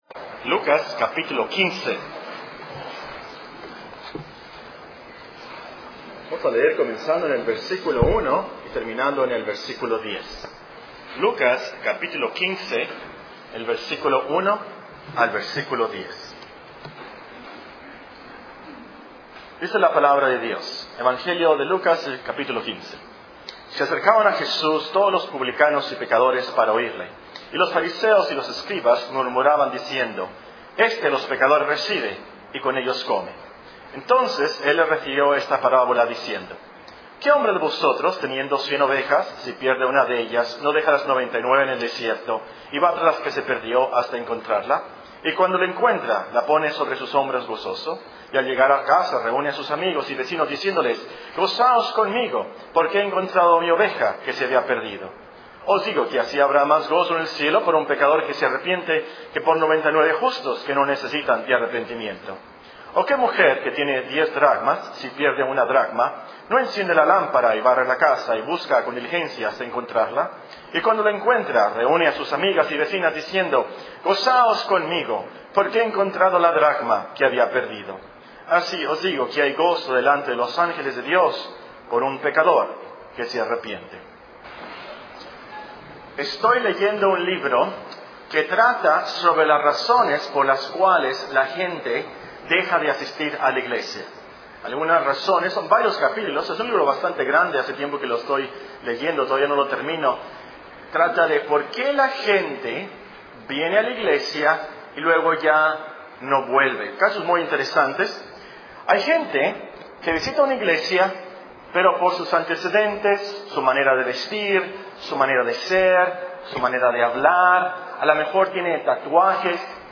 Serie de sermones General